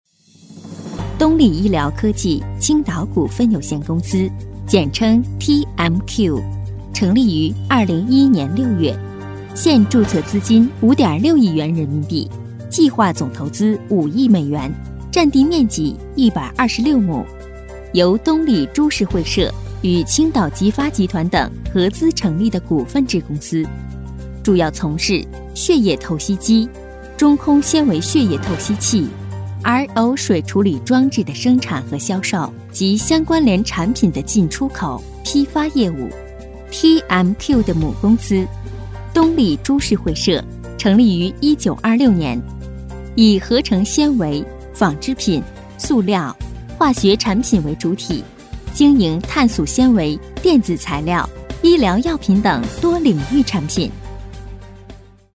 女国19_专题_医院_东丽_成熟.mp3